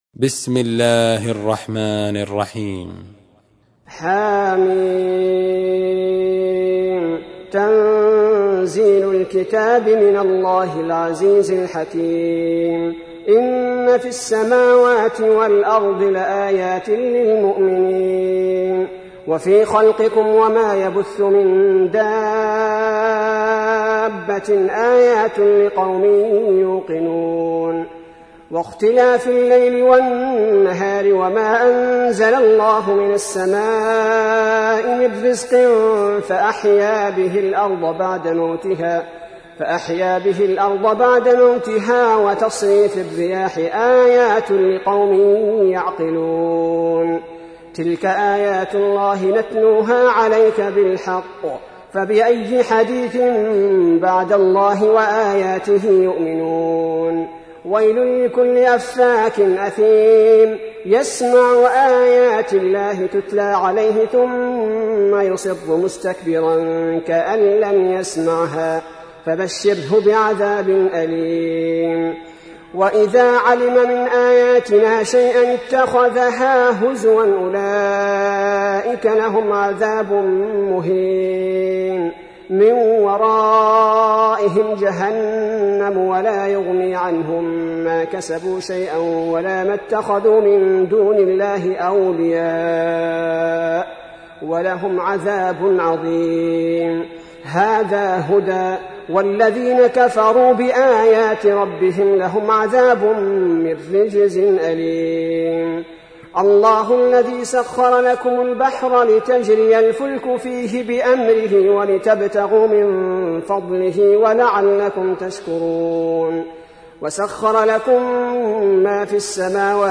تحميل : 45. سورة الجاثية / القارئ عبد البارئ الثبيتي / القرآن الكريم / موقع يا حسين